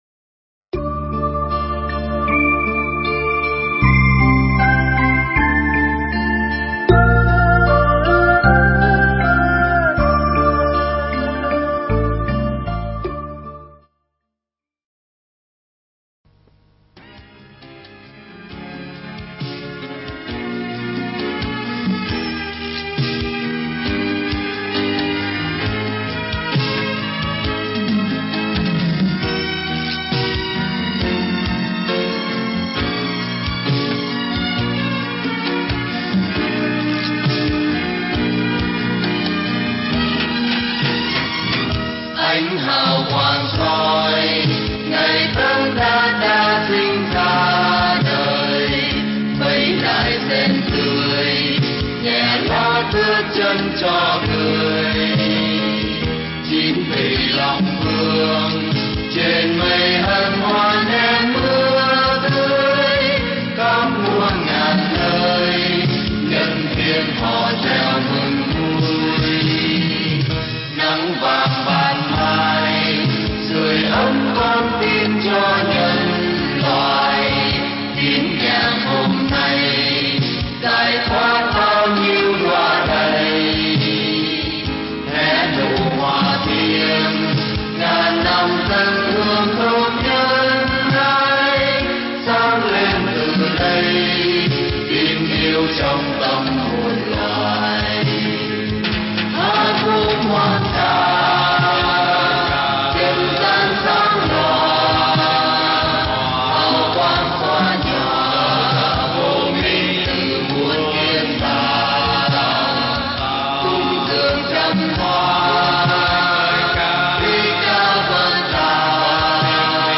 Mp3 Pháp Thoại Đạo Phật Là Đạo Như Thật – Hòa Thượng Thích Thanh Từ giảng tại Thiền Viện Trúc Lâm Đà Lạt nhân lễ Phật Đản